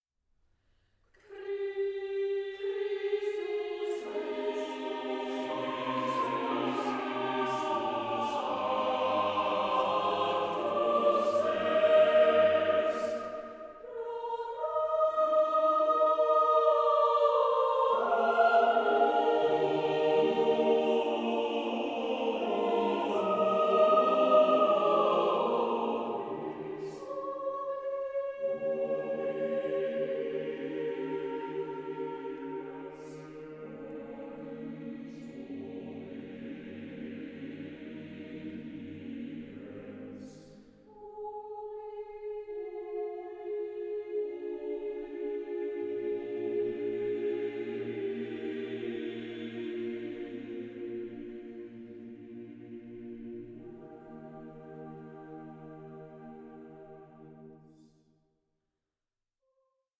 TWO MASTERS OF AUSTRIAN CHURCH MUSIC COMBINED